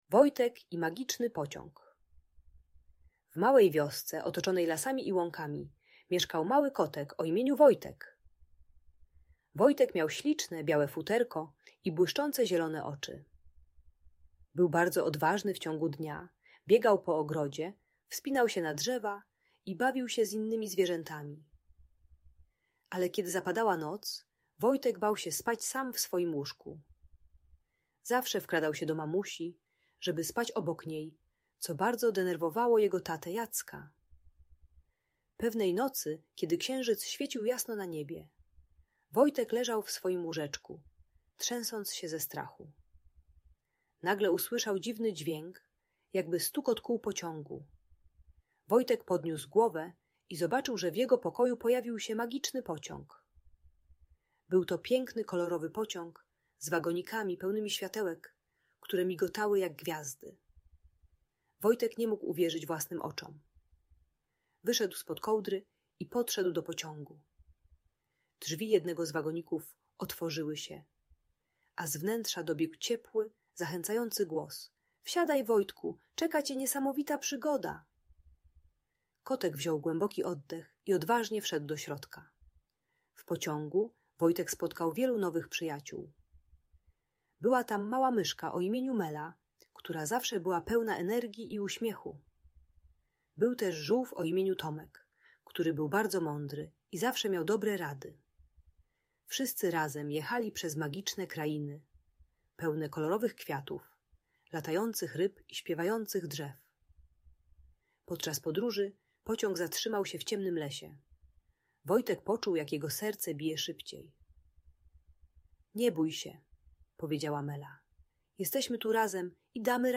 Bajka dla dziecka które boi się spać samo w swoim łóżku. Audiobajka usypiająca dla dzieci 3-5 lat, które wkradają się do rodziców w nocy. Historia o kotku Wojtku uczy techniki wyobrażeniowej - dziecko wizualizuje magicznych przyjaciół, którzy dodają mu odwagi.